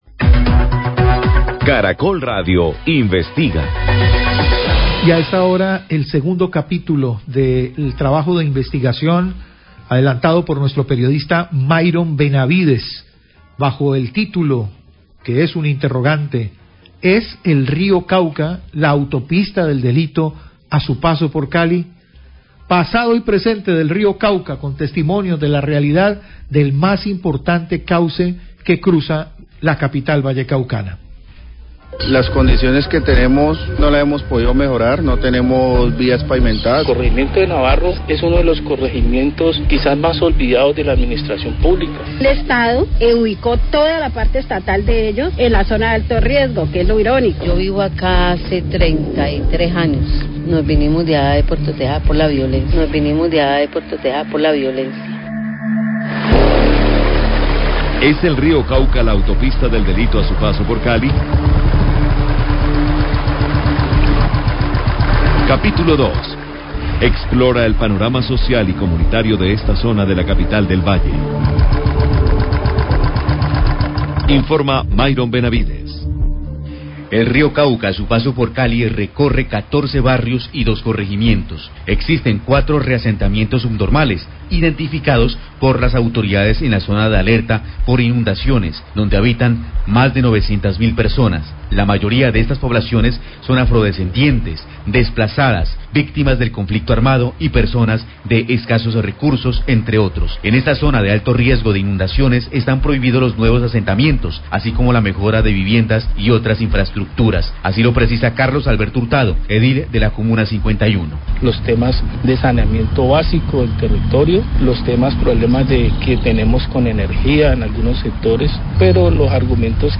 Radio
informe especial